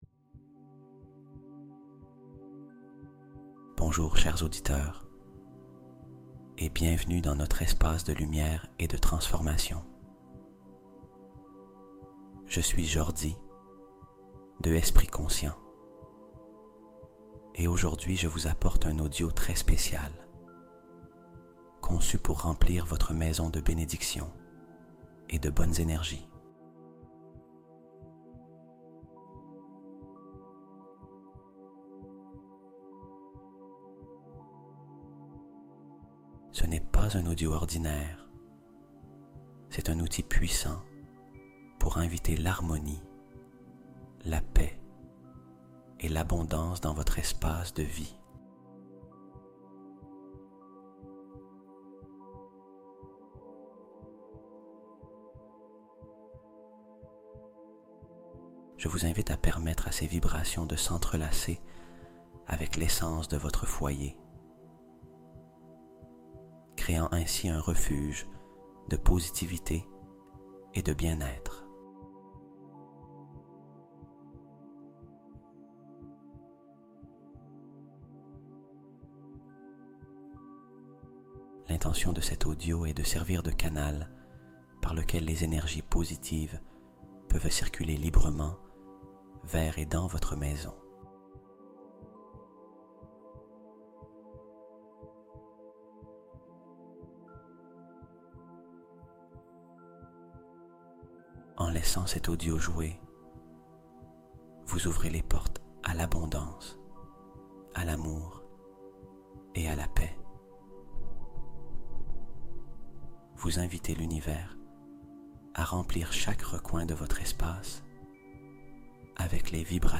Méditation Guidée